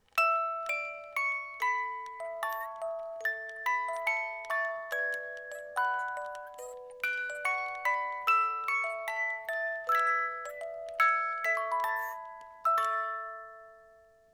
die Spieluhr besitzt ein Qualitätsspielwerk mit 18 Zungen
Der Ton dieser Spieluhren ist klar, warm und obertonreich und wird durch Kurbeln eines Qualitätsspielwerks erzeugt.
• die Spieluhr ist ein mechanisches Musikinstrument und ausdrücklich kein Spielzeug